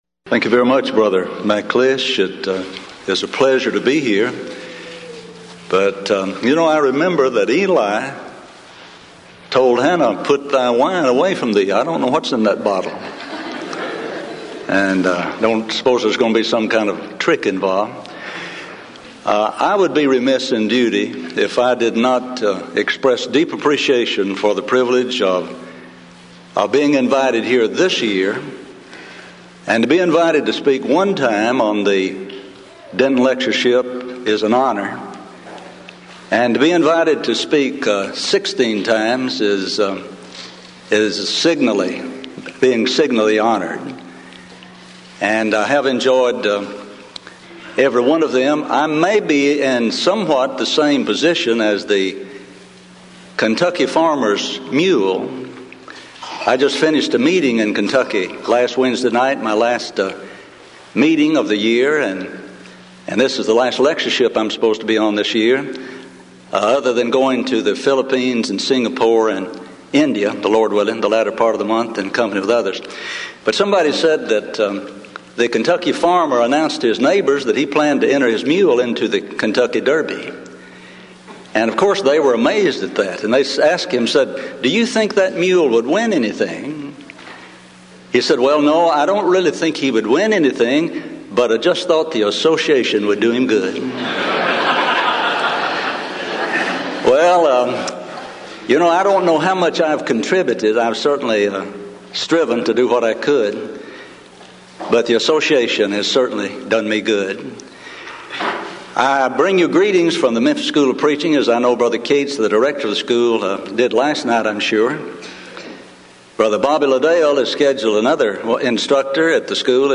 Series: Denton Lectures Event: 16th Annual Denton Lectures